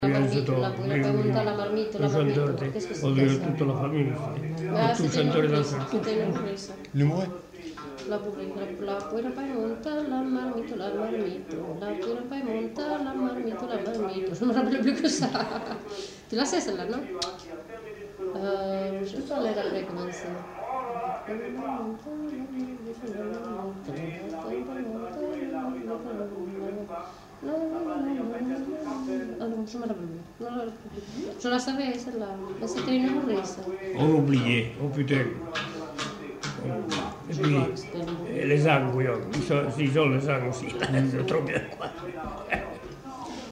Mélodie non identifiée (notes chantées